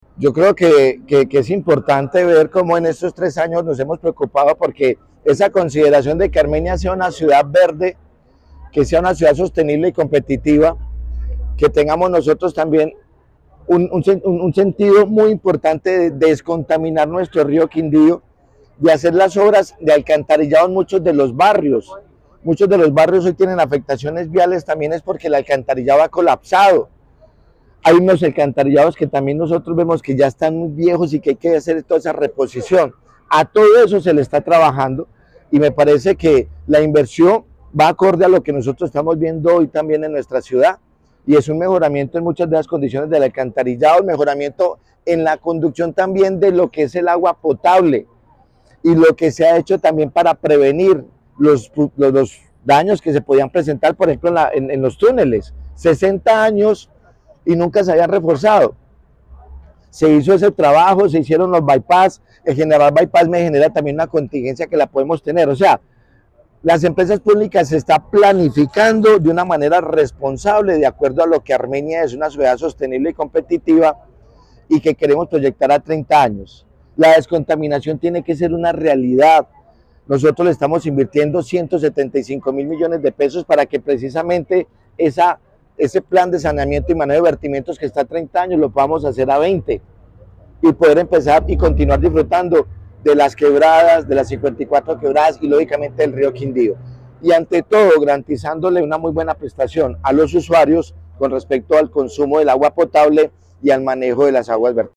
Audio de: José Manuel Ríos Morales, alcalde de Armenia.
alcalde_Jose_Manuel_Ri_os_Morales__Inversiones_EPA.mp3